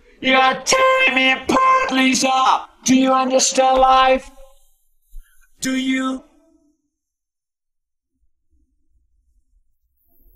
Autotuned to a chord progression
theroom1-chordprog.wav